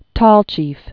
(tôlchēf), Maria 1925-2013.